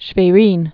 (shvā-rēn)